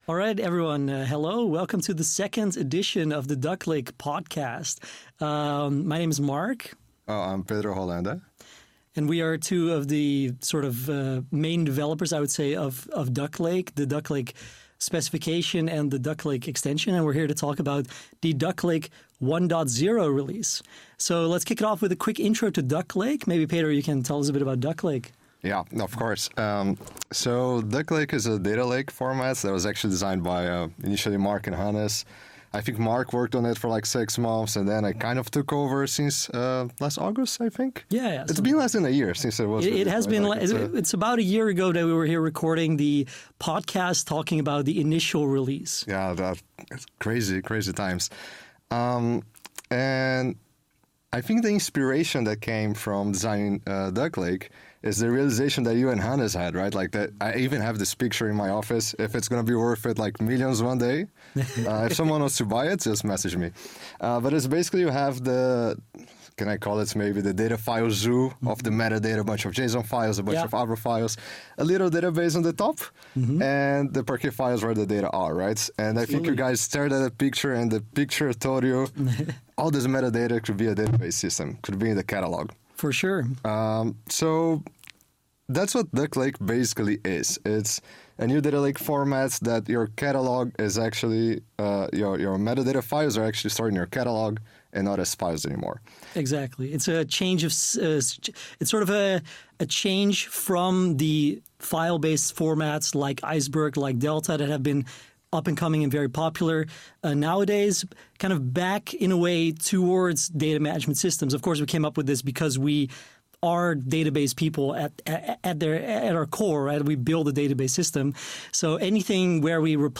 DuckLake v1.0 – Developer Discussion